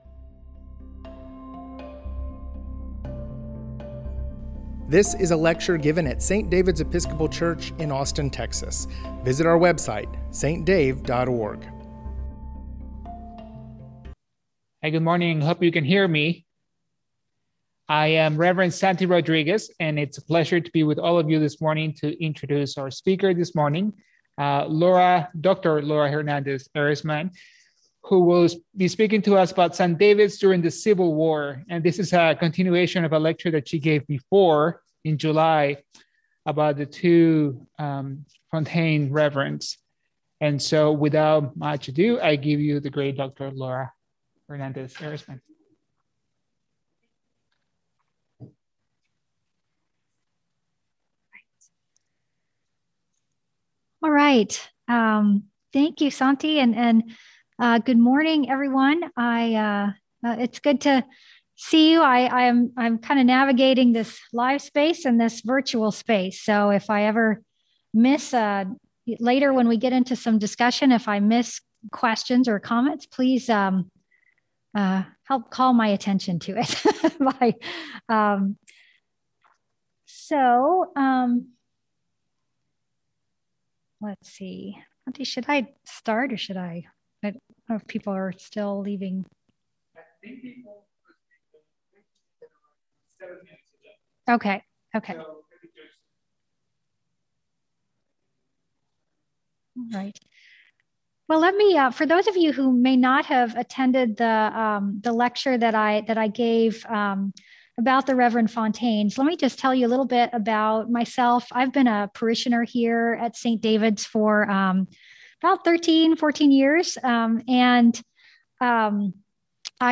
St. David's Anti-Racism History Project Lecture Series: The Two Reverends Fontaine (Part 2)